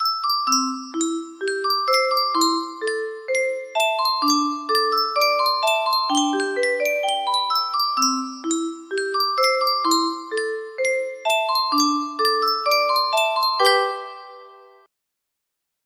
Yunsheng Music Box - Unknown Tune 1520 music box melody
Full range 60